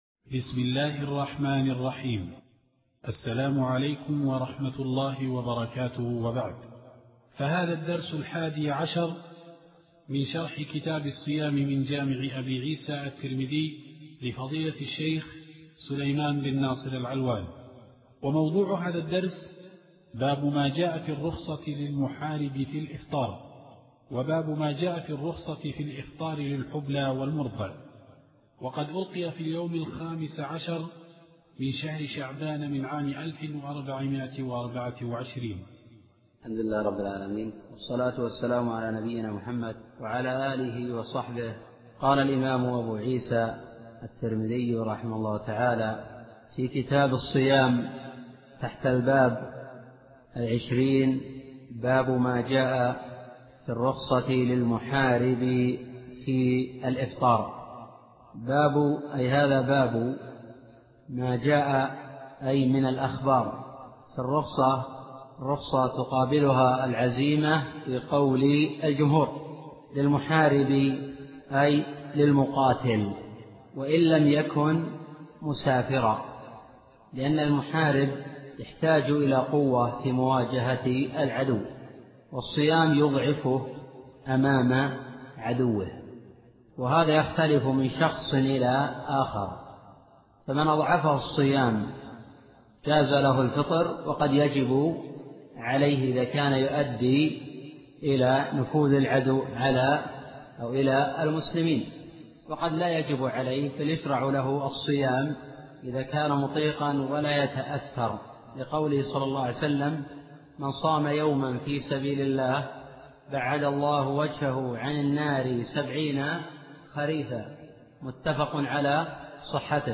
الدرس الحادي عشر من شرح كتاب الصيام من جامع الترمذي